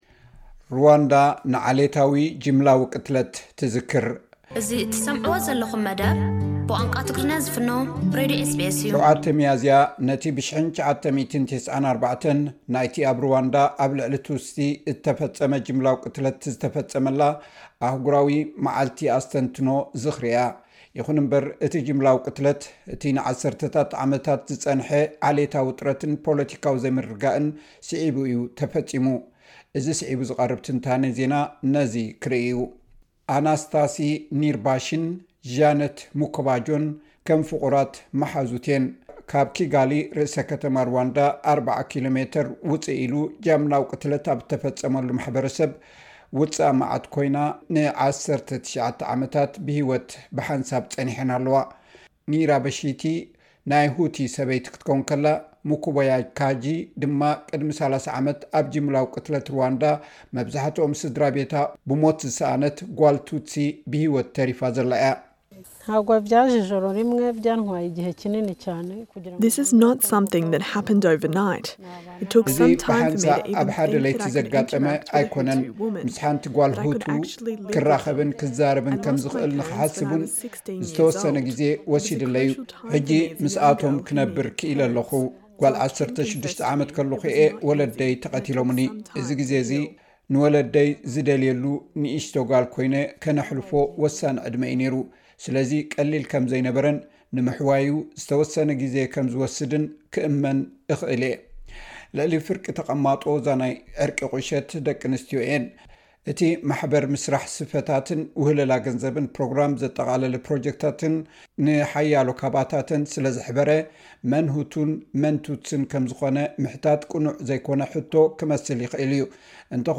7 ሚያዝያ ነቲ ብ1994 ናይቲ ኣብ ሩዋንዳ ኣብ ልዕሊ ቱትሲ እተፈጸመ ጃምላዊ ቅትለት ዝተፈጸመላ 'ኣህጉራዊ መዓልቲ ኣስተንትኖ " እያ ። ይኹን እምበር ፡ እቲ ጃምላዊ ቅትለት እቲ ንዓሰርተታት ዓመታት ዝጸንሐ ዓሌታዊ ውጥረትን ፖለቲካዊ ዘይምርግጋእን ስዒቡ እዩ ተፈጺሙ ። እዚ ስዒቡ ዝቐርብ ትንታነ ዜና ነዚ ክርእይ እዩ ።